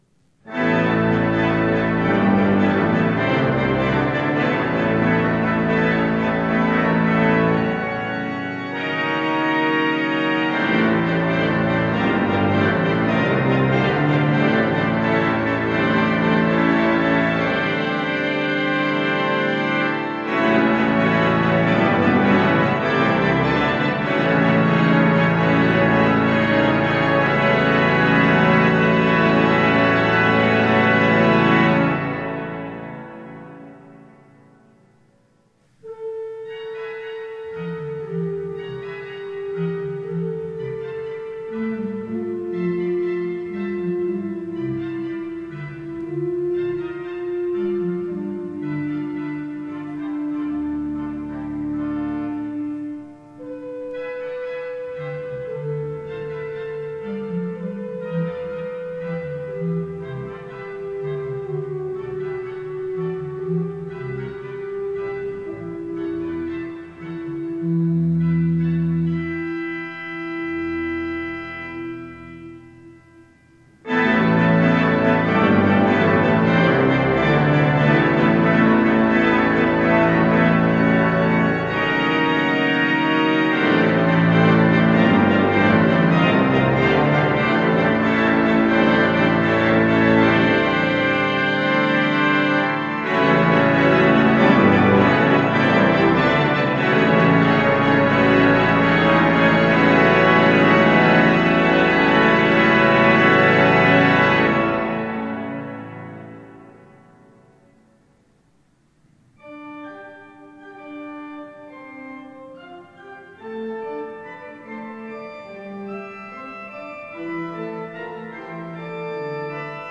Choralfantasie
Die 1904 von Walcker, Ludwigsburg gebaute Orgel der Georgskirche ist in großen Teilen noch im Original erhalten.